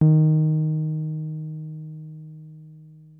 303 D#3 6.wav